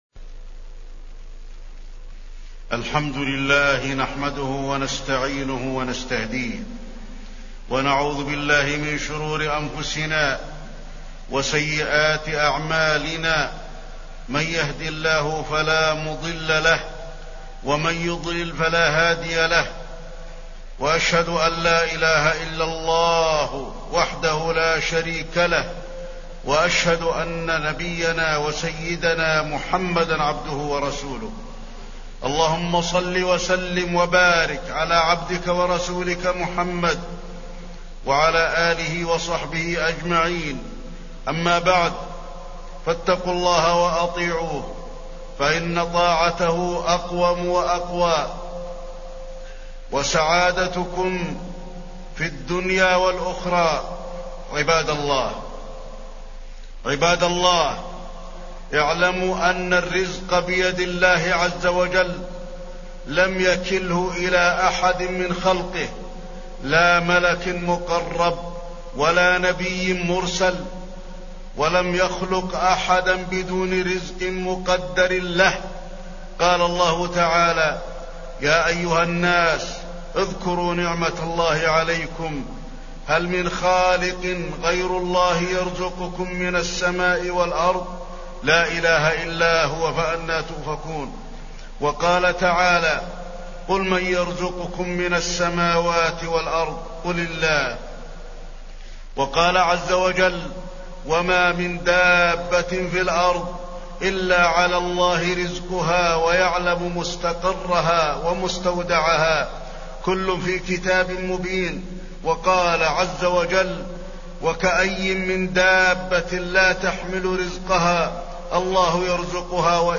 تاريخ النشر ١٥ صفر ١٤٢٩ هـ المكان: المسجد النبوي الشيخ: فضيلة الشيخ د. علي بن عبدالرحمن الحذيفي فضيلة الشيخ د. علي بن عبدالرحمن الحذيفي الرزق وأسبابه